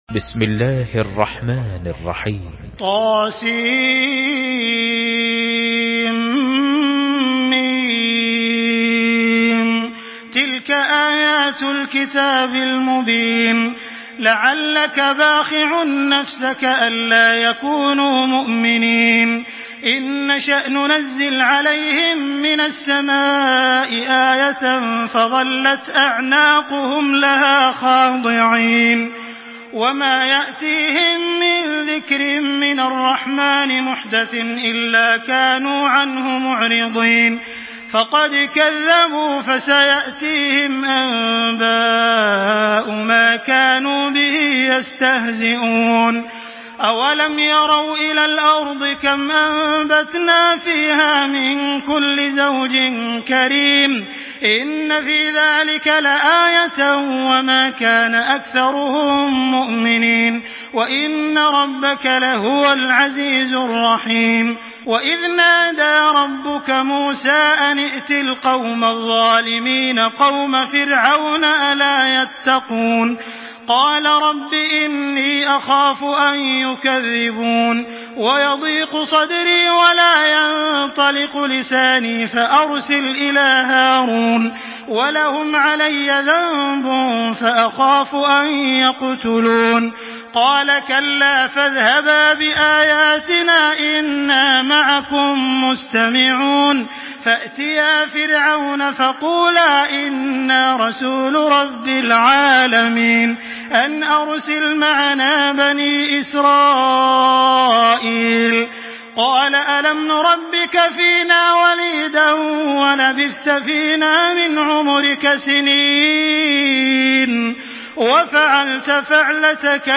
تراويح الحرم المكي 1425
مرتل